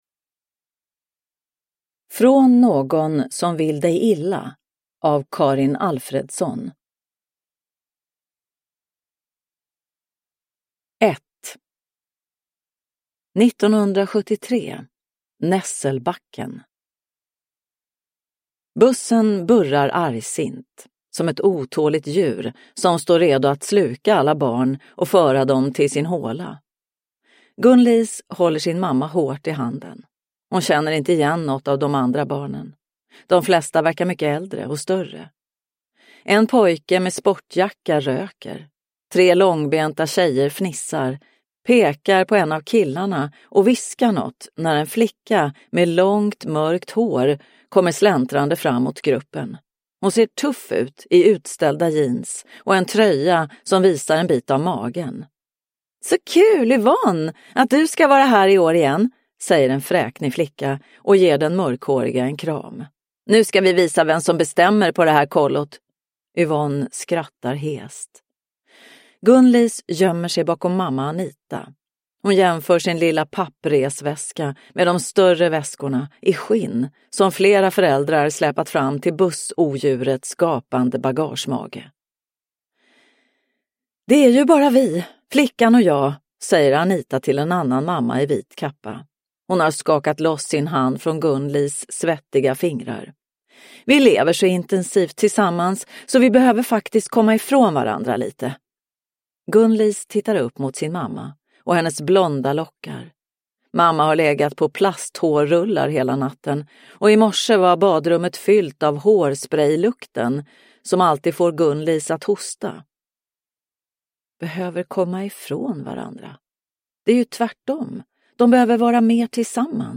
Från någon som vill dig illa – Ljudbok – Laddas ner